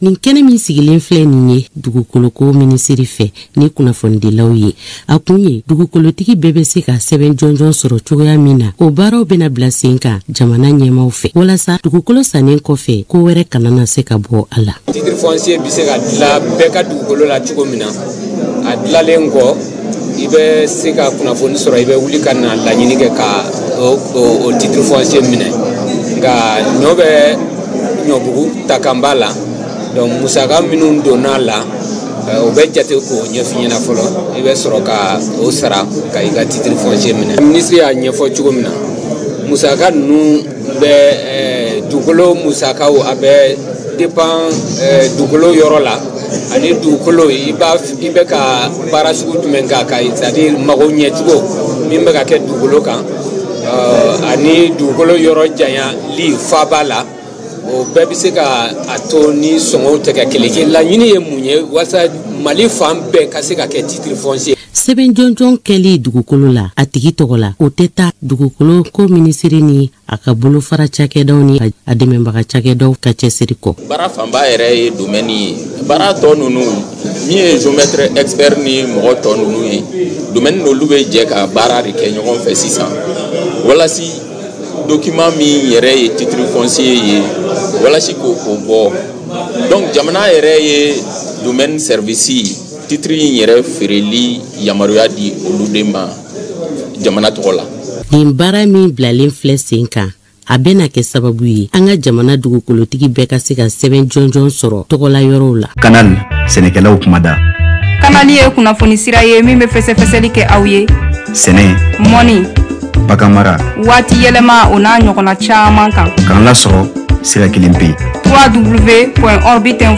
Le Ministre de l’Urbanisme,de l’Habitat, des Domaines, de l’Aménagement du territoire, et de la Population, M. Imirane Abdoulaye Touré a animé ce jeudi un point de presse à la Direction nationale de l’Urbanisme et de l’Habitat. La rencontre avait pour objectif d’informer la presse du lancement très prochain de l’opération systématique d’immatriculation des parcelles de terrain en République du Mali.